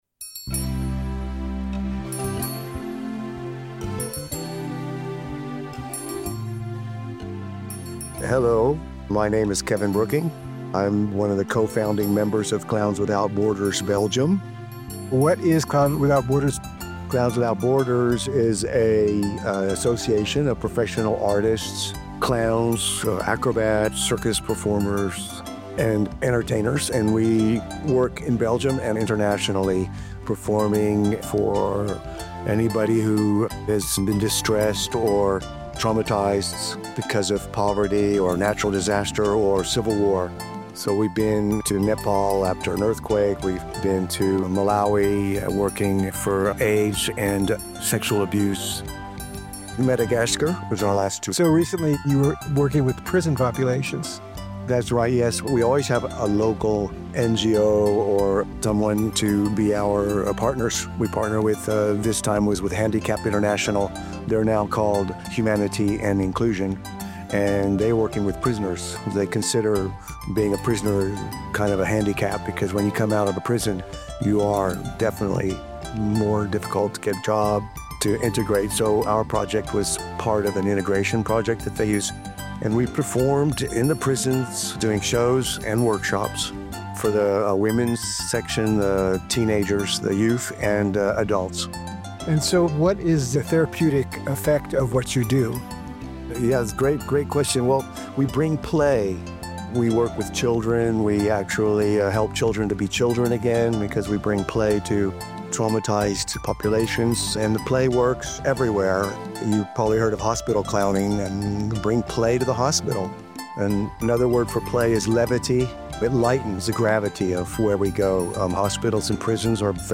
He brings us up to date on what the humanitarian group has been up to recently., including a mission to the prisons of Madagascar.